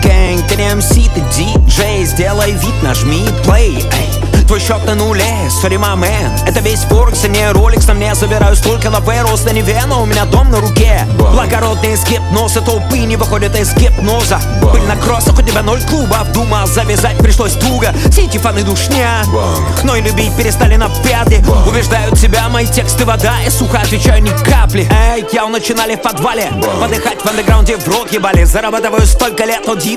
Rap Hip-Hop